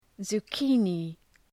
{zu:’ki:nı}
zucchini.mp3